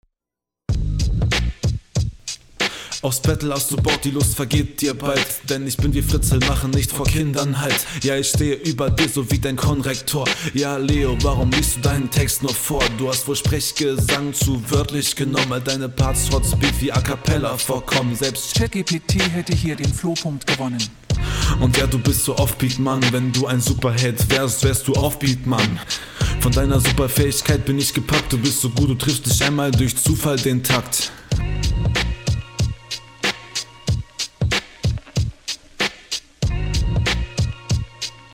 Offbeatmann eher peinlich.
Flow für Bronze voll im Rahmen